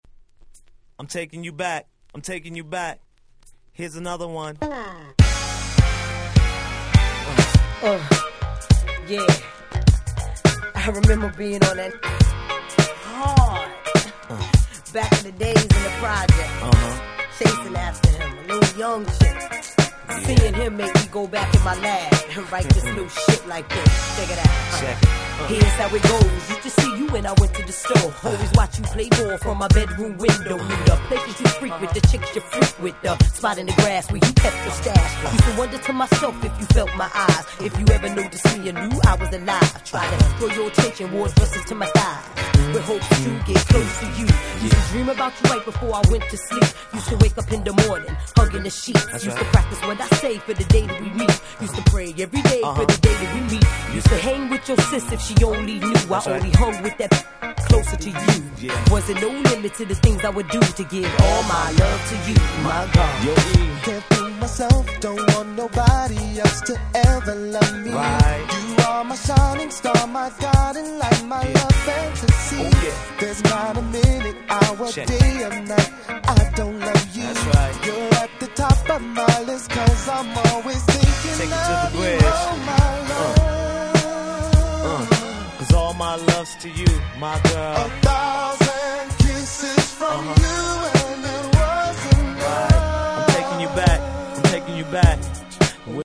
97' Smash Hit Hip Hop !!